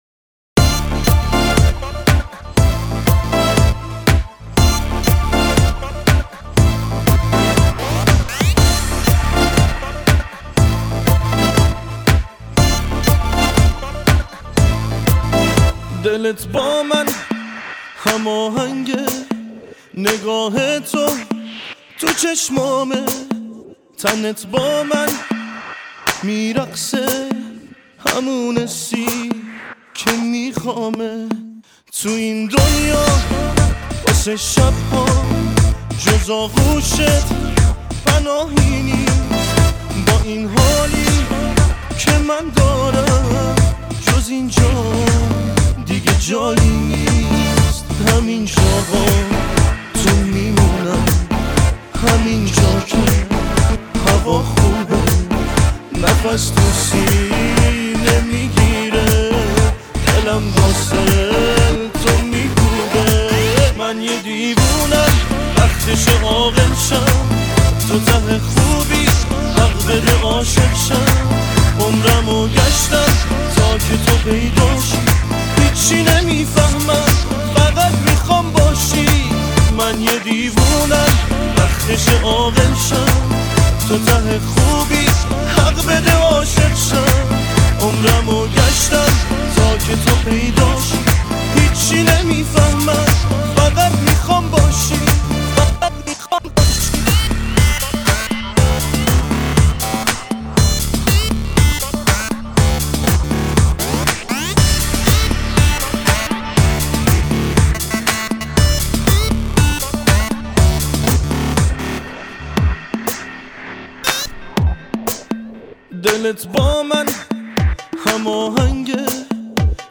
اهنگ شاد ایرانی